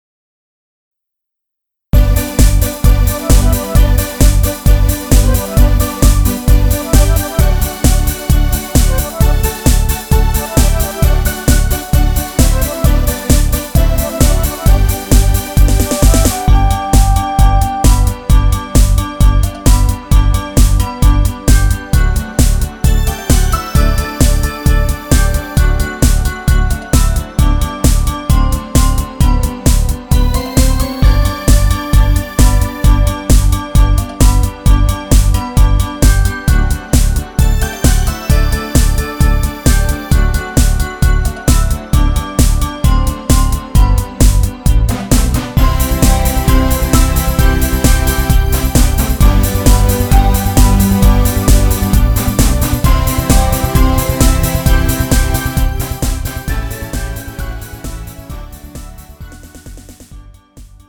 가수 크리스마스 캐롤
음정 G 키
장르 가요 구분 Pro MR